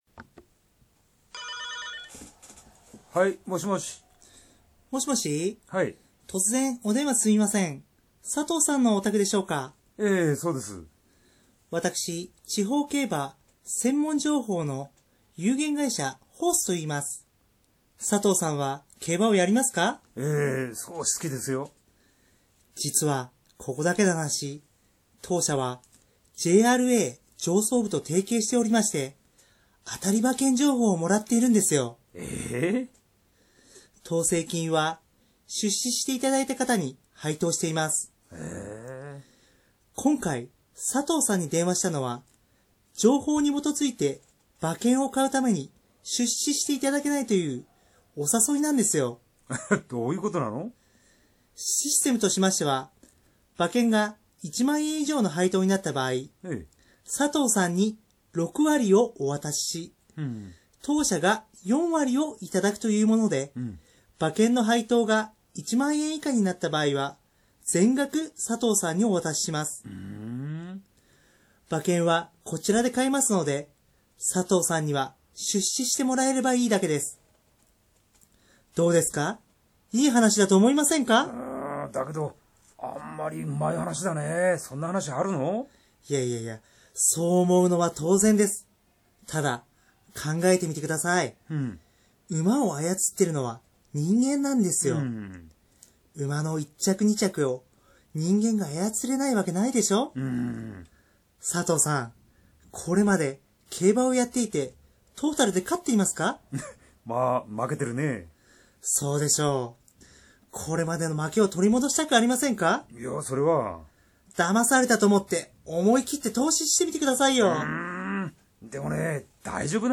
やりとりの例 MP3形式 4.0MB 2分51秒